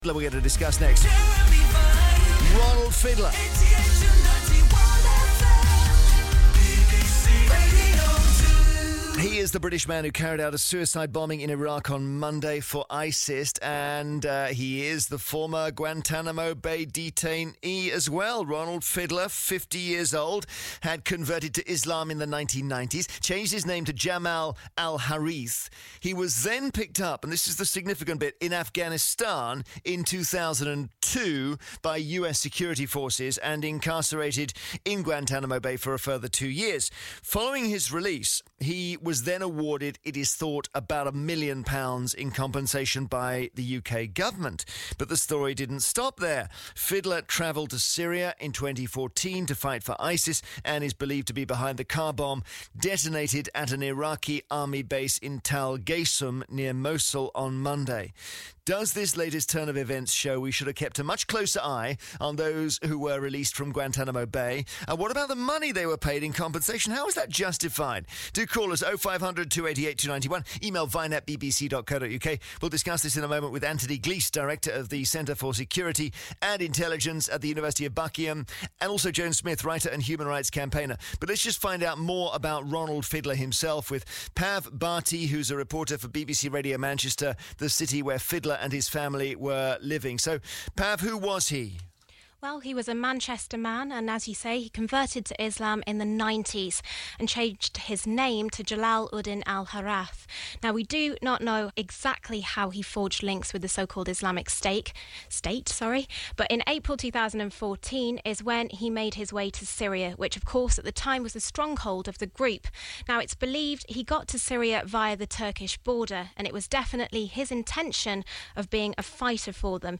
Report on Jeremy Vine RE: The government refusing to comment on whether public money was given to the terrorist from Manchester, who this week carried out a suicide bombing in Iraq.